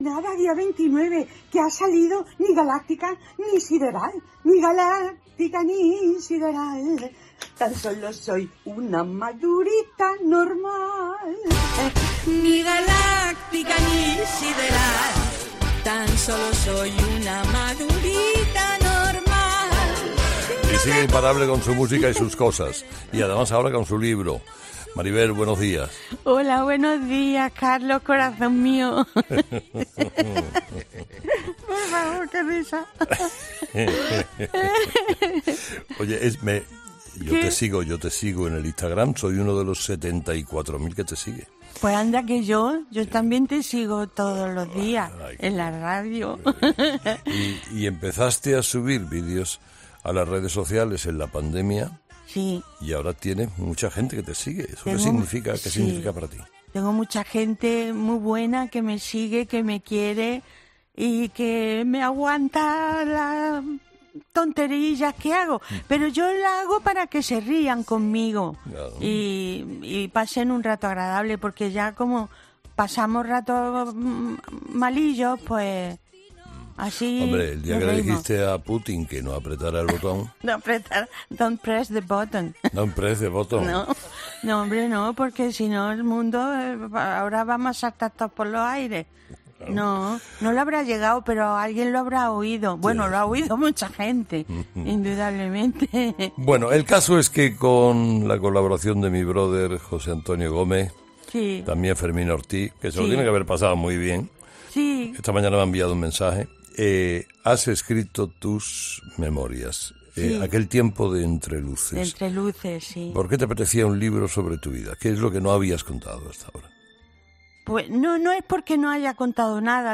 Karina ha sido entrevistada en 'Herrera en COPE' por el lanzamiento de su propio libro